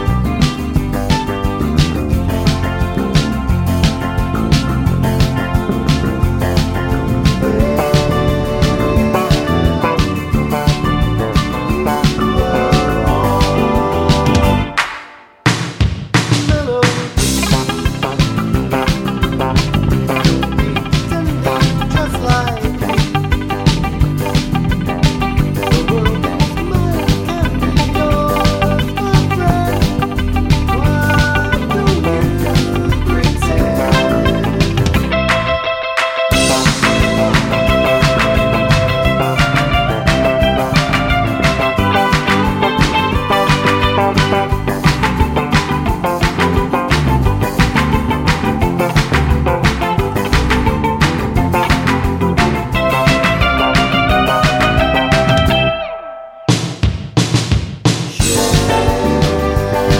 no Backing Vocals Glam Rock 2:44 Buy £1.50